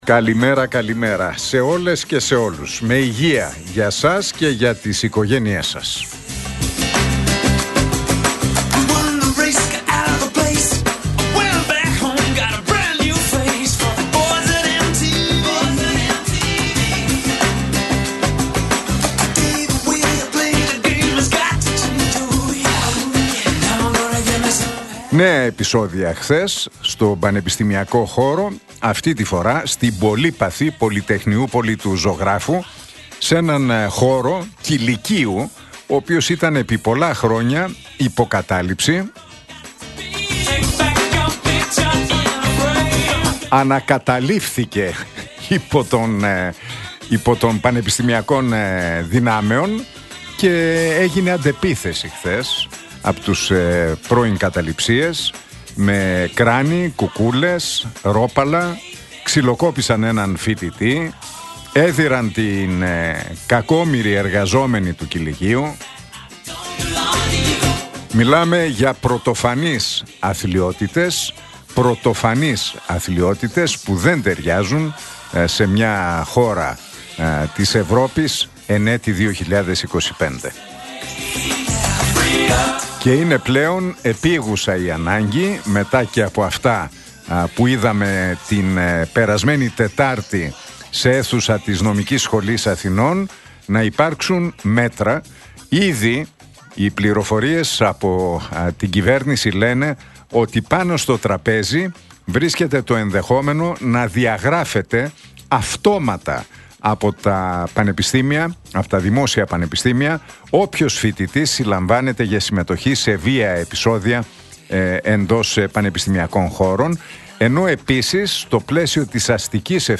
Ακούστε το σχόλιο του Νίκου Χατζηνικολάου στον ραδιοφωνικό σταθμό Realfm 97,8, την Τρίτη 6 Μαΐου 2025.